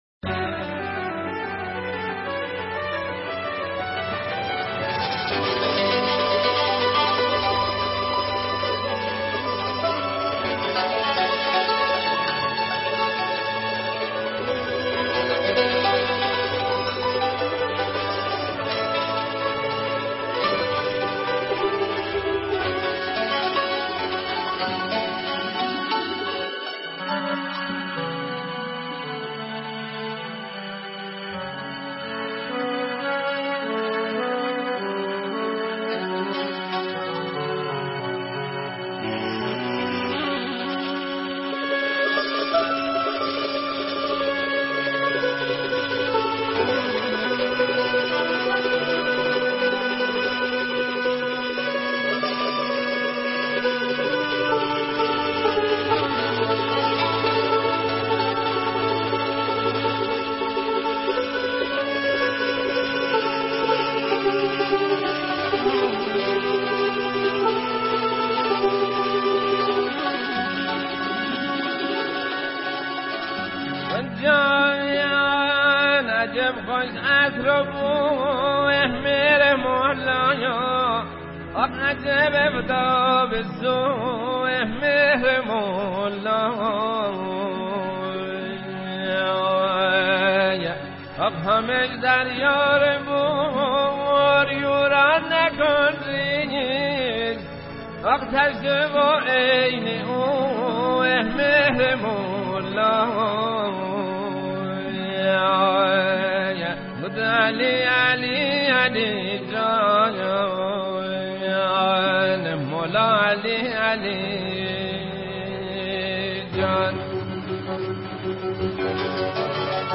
آهنگ طبری
آهنگ مازندرانی